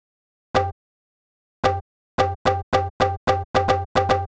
106 BPM Beat Loops Download